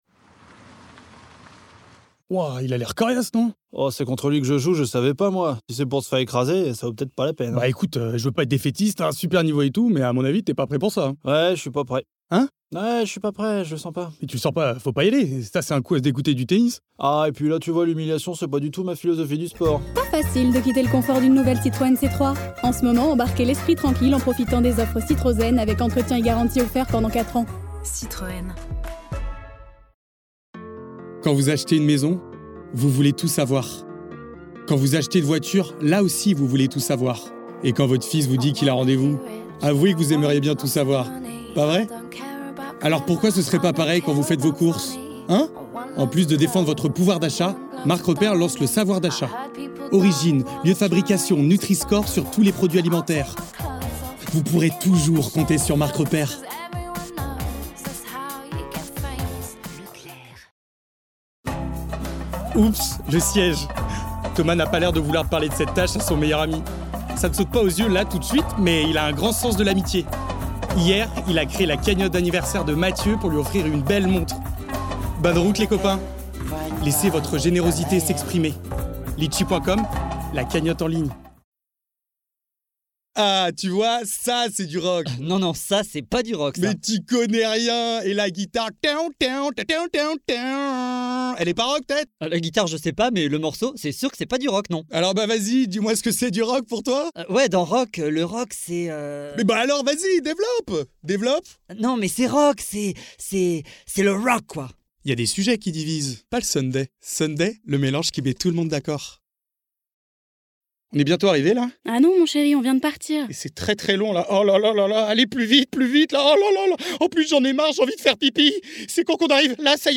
Bandes-son
Voix off
- Baryton-basse